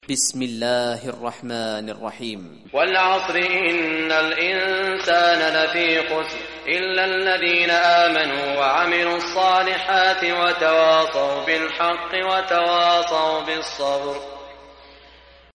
Surah Asr Recitation by Sheikh Saud Shuraim
Surah Asr, listen or play online mp3 recitation of Surah Asr in the beautiful voice of Imam e Kaaba Sheikh Saud Al Shuraim.